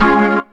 B3 CMAJ 2.wav